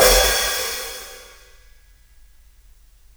Index of /4 DRUM N BASS:JUNGLE BEATS/KIT SAMPLES/DRUM N BASS KIT 1
HIHAT OPEN.wav